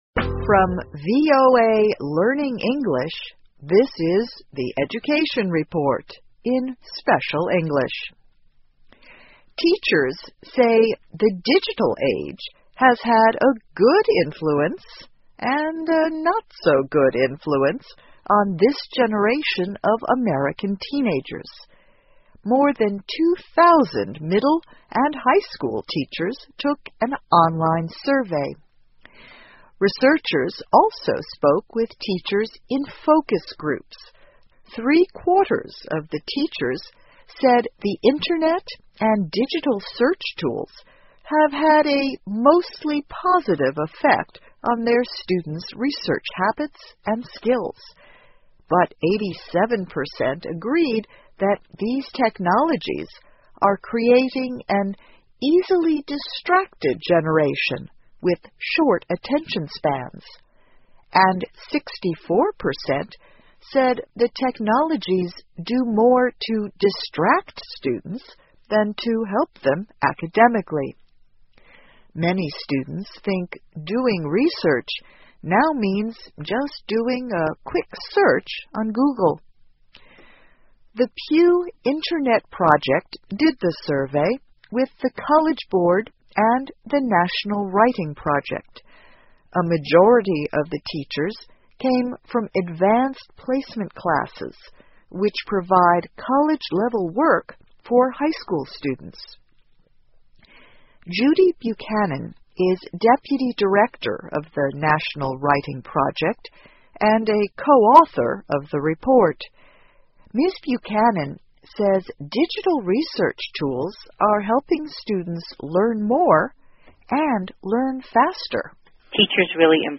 VOA慢速英语2013 教育报道 - 数字时代对青少年的影响 听力文件下载—在线英语听力室